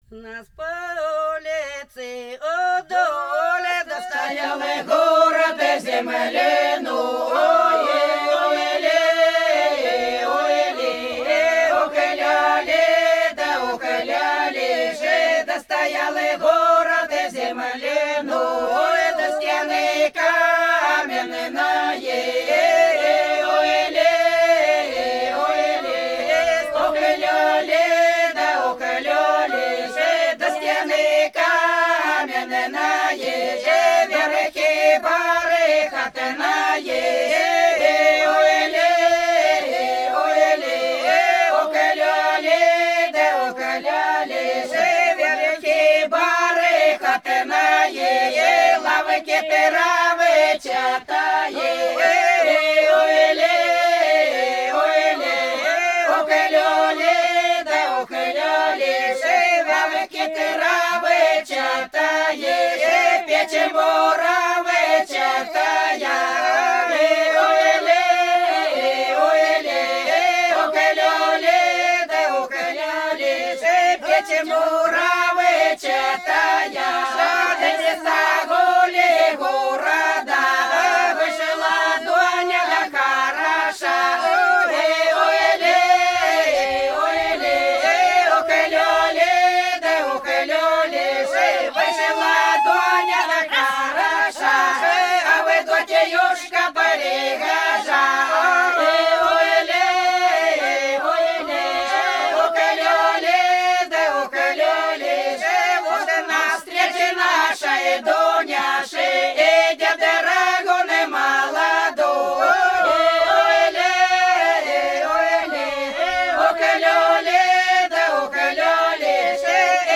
Пролетели все наши года У нас по улице – плясовая (Фольклорный ансамбль села Иловка Белгородской области)
05_У_нас_по_улице_–_плясовая.mp3